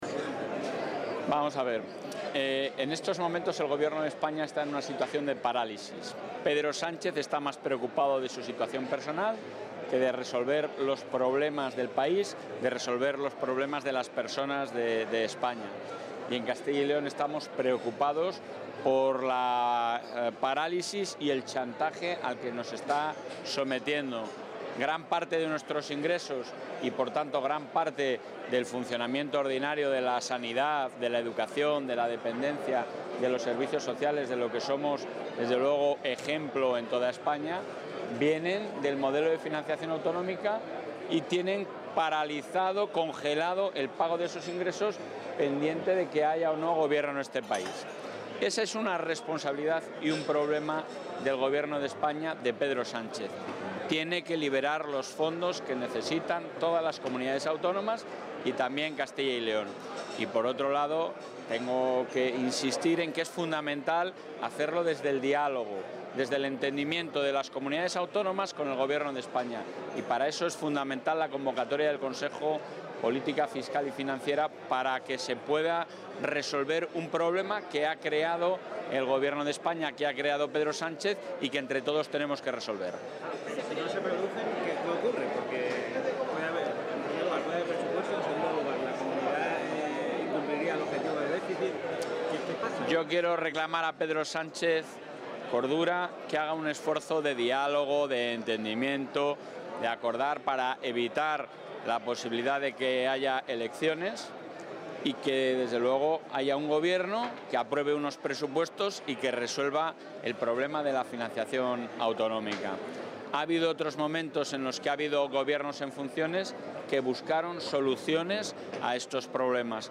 Atención a medios.